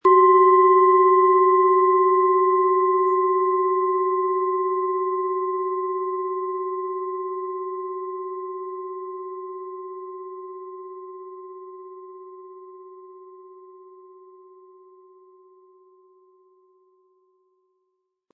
Planetenton 1
Handgetriebene, tibetanische Planetenklangschale Tageston.
Sanftes Anspielen wird aus Ihrer bestellten Klangschale mit dem beigelegten Klöppel feine Töne zaubern.
Das Klangbeispiel gibt den Ton der hier angebotenen Klangschale wieder.